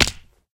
fallsmall.ogg